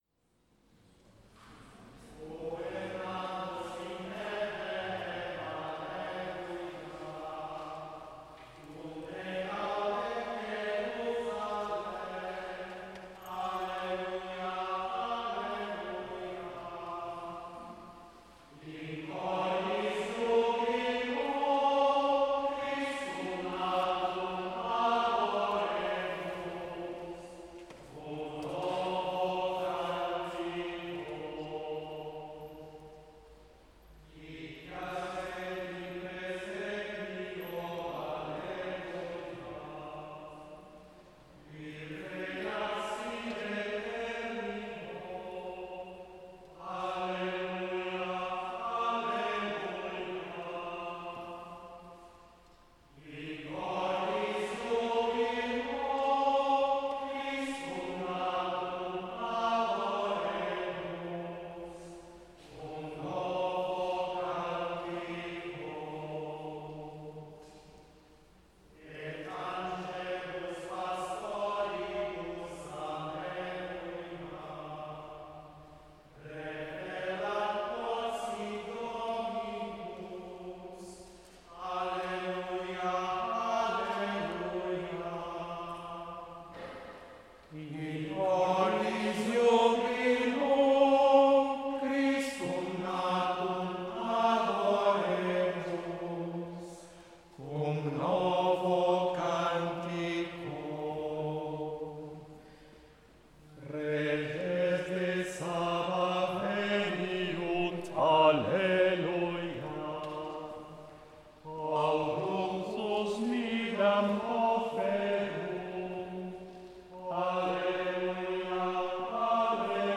The gregorian chant
Being one of the most ancient musical traditions of the Western World, gregorian chant constitutes a form of sung prayer.